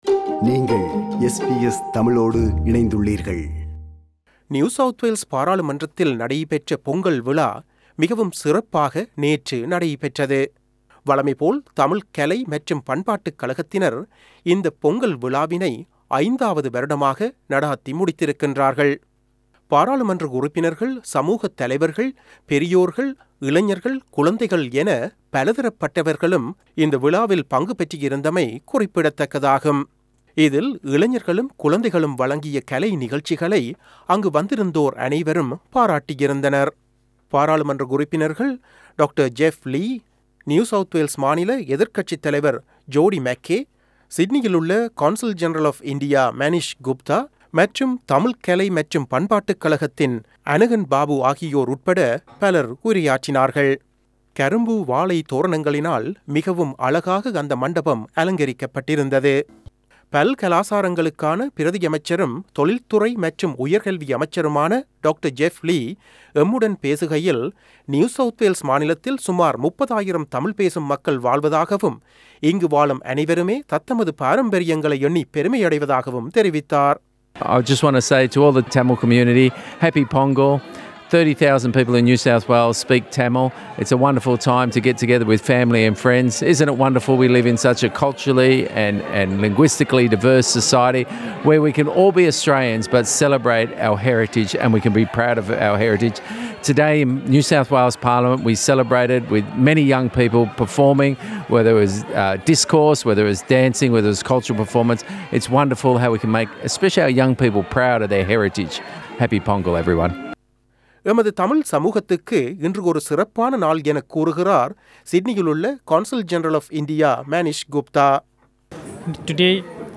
இவ்விழா பற்றிய விவரணம் ஒன்றைத் தருகிறார்